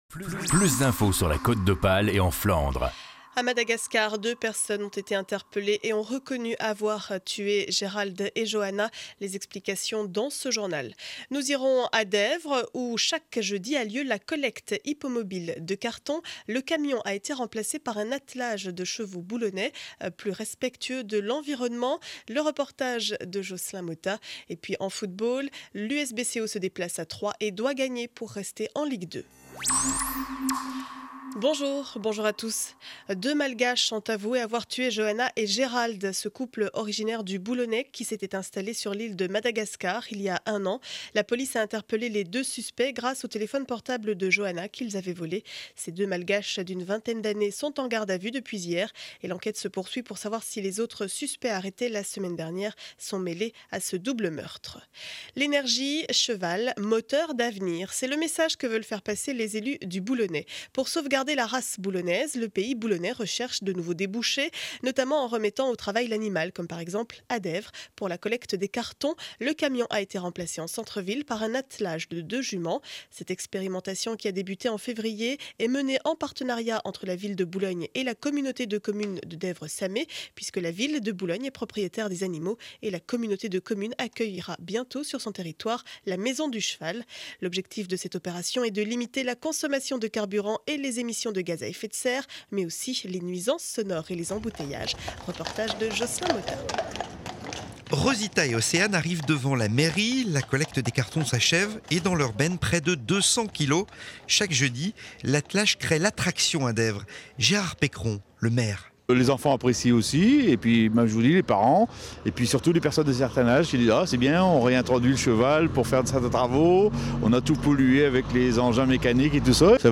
Journal du vendredi 27 avril 2012 7 heures 30, édition du Boulonnais.